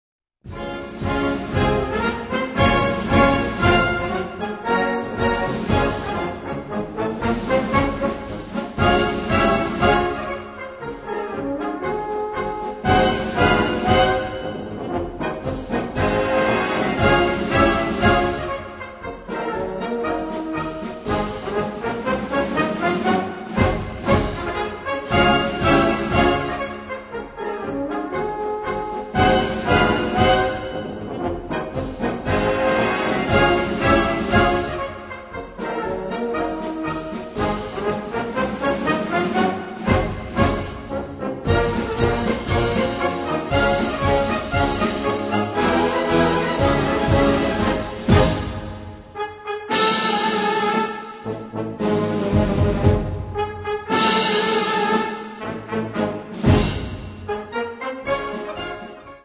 Gattung: Marsch
Besetzung: Blasorchester